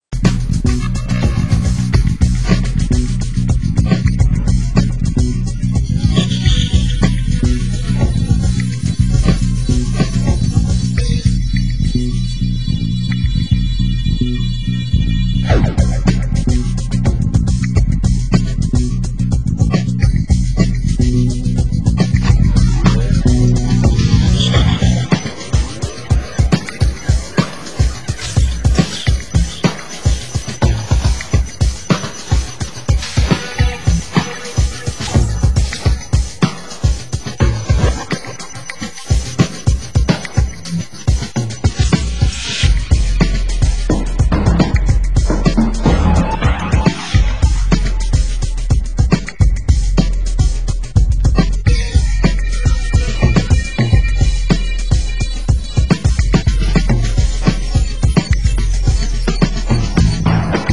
Genre: UK House